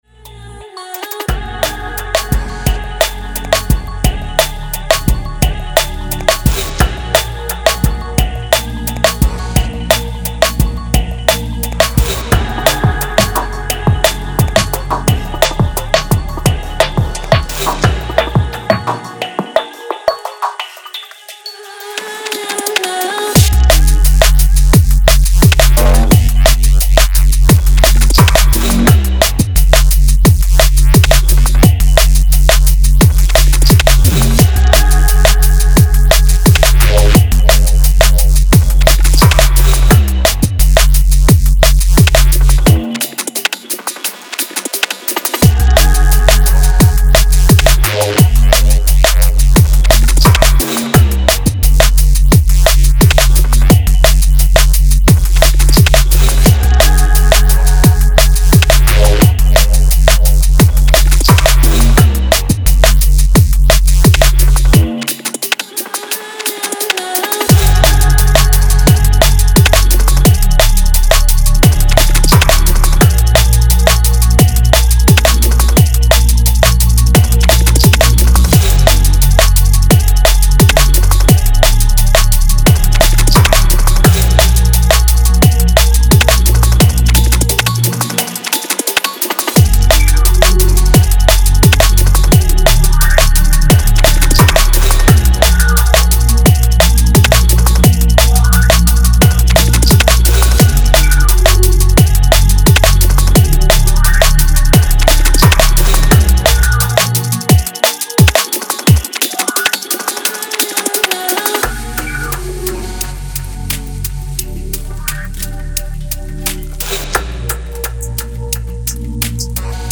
dark and brooding cuts